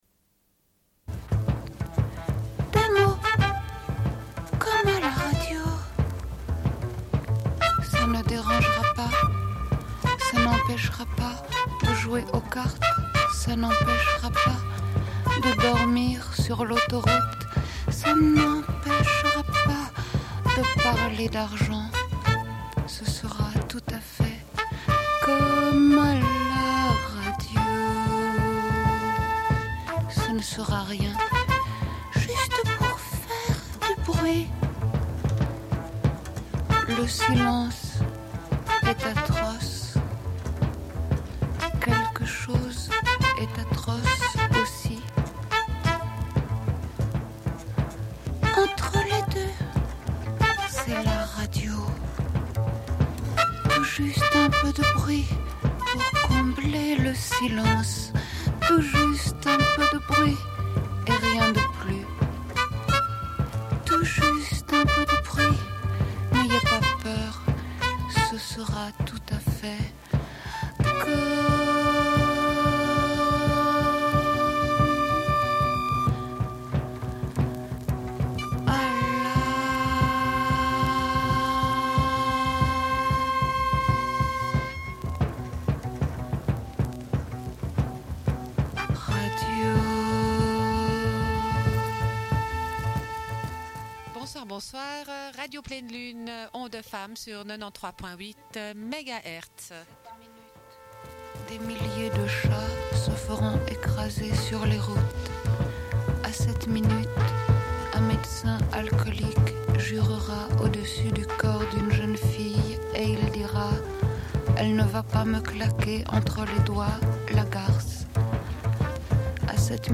Une cassette audio, face B28:50